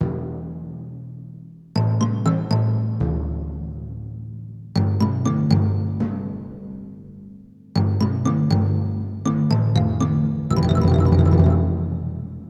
Sección de percusión (orquesta sinfónica) Breve pieza ejemplo.
percusión
orquesta